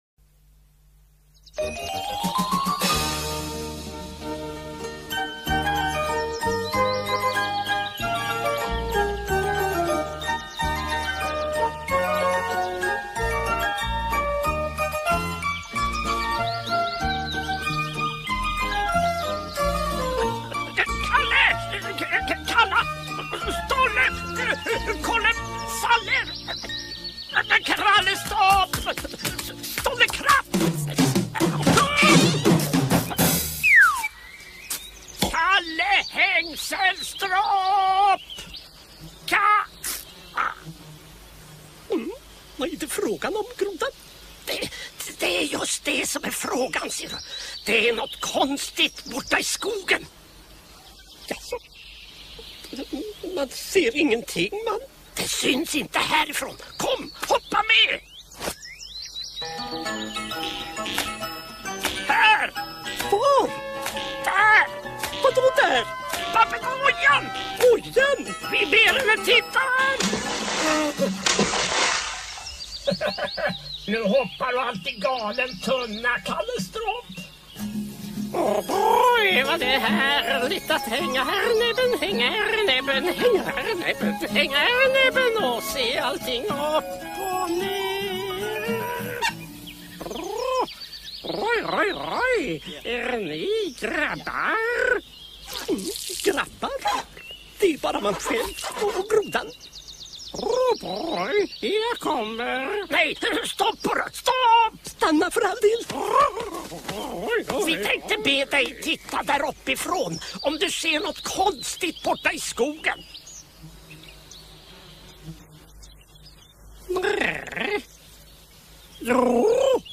Kalle Stropp och Grodan Boll på svindlande äventyr är en svensk animerad film från 1991 regisserad av Jan Gissberg och med manus och musik av Thomas Funck, som också gör de flesta rösterna. Kalle Stropp och Grodan Boll och deras vänner var vid filmens premiär redan kända figurer från radio, teaterscen, tecknade serier, spelfilm och en animerad kortfilm.